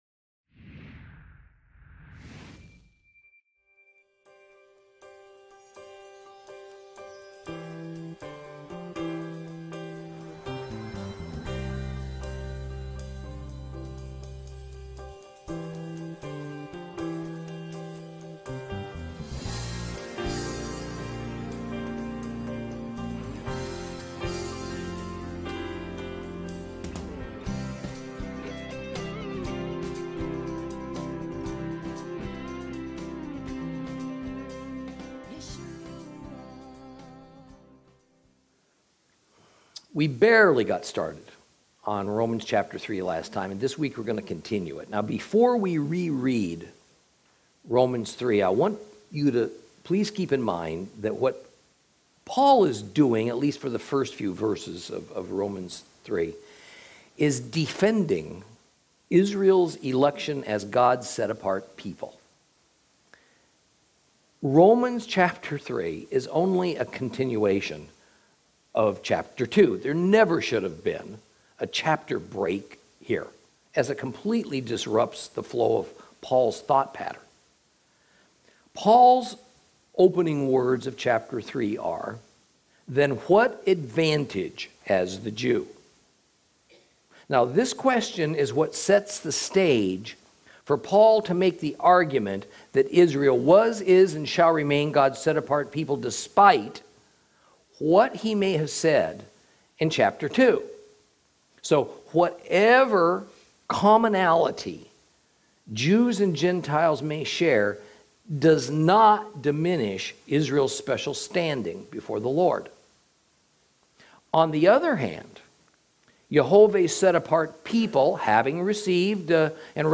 Lesson 9 Ch3 - Torah Class